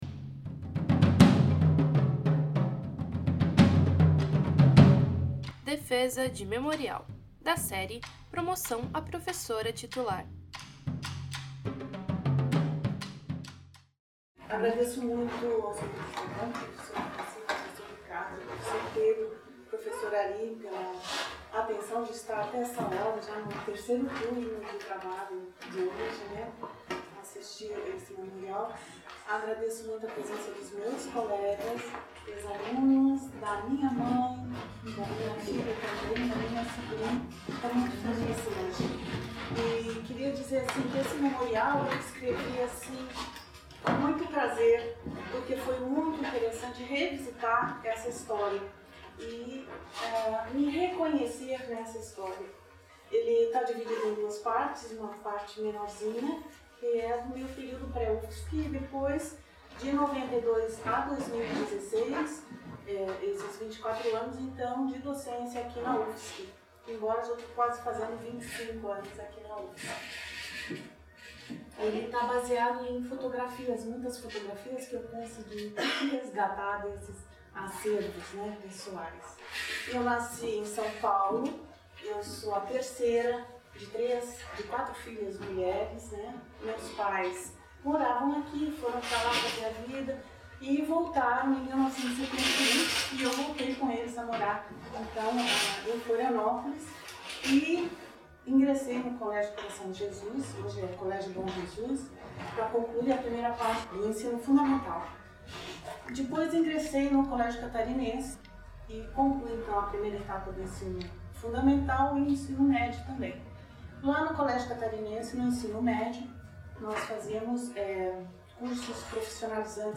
no Auditório do MArquE. Área de atuação: Biogeografia e Pedologia.